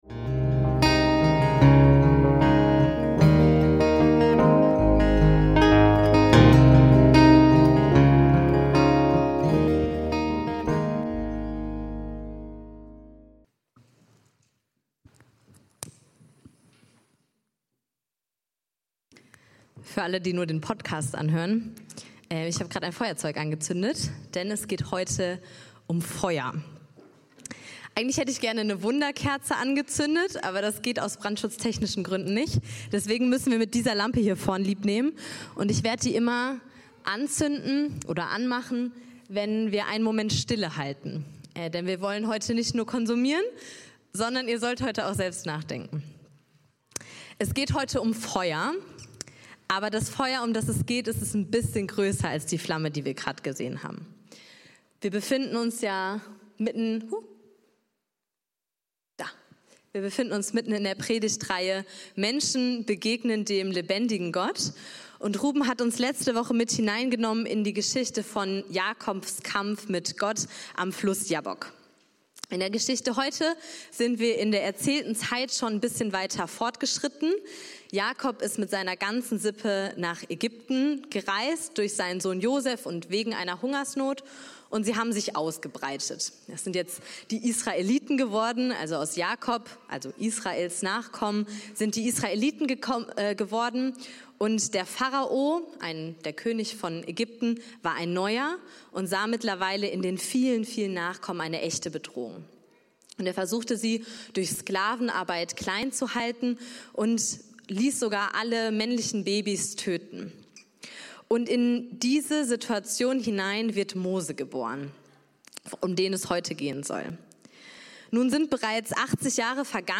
Mose und der brennende Dornbusch 11.05.2025 ~ FeG Bochum Predigt Podcast